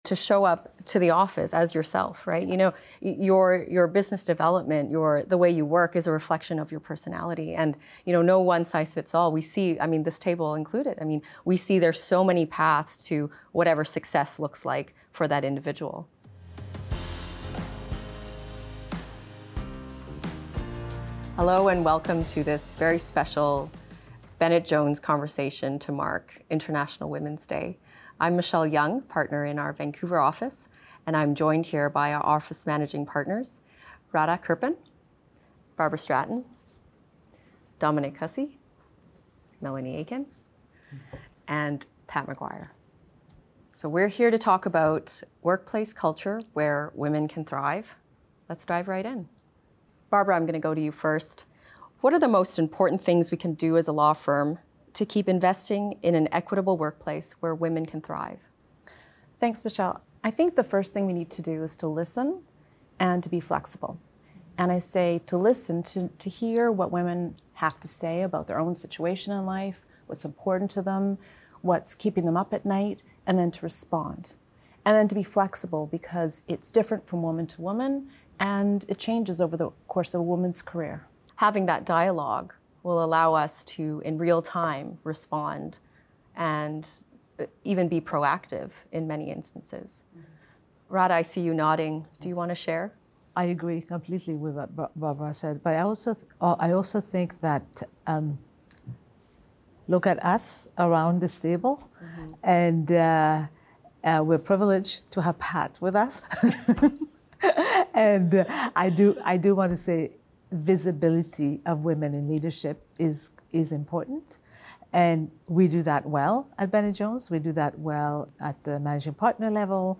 Pour souligner la Journée internationale de la femme de cette année, nous sommes fiers de présenter une conversation franche sur les défis auxquels les femmes sont confrontées dans le domaine juridique.